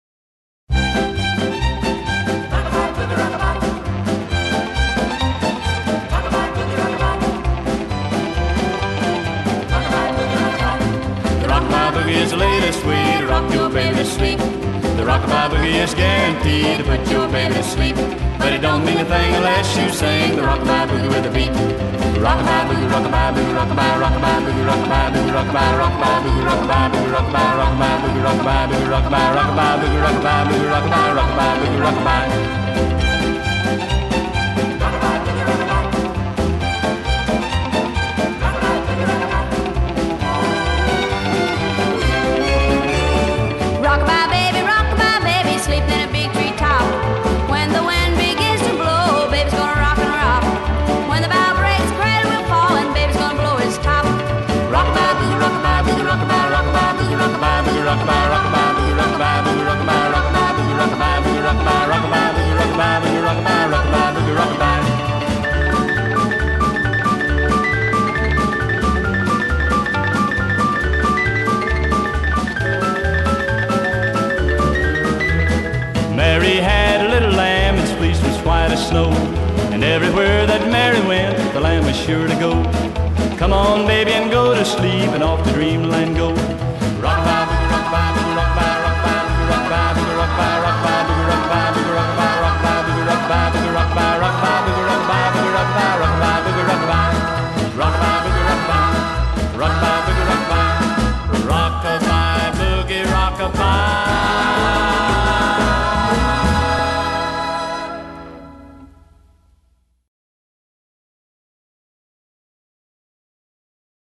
Country, Traditional Country